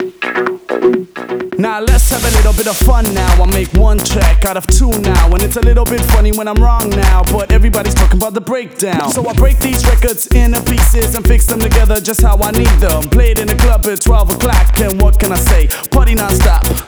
VTDS2 Song Kit 128 BPM Rap 1 Out Of 2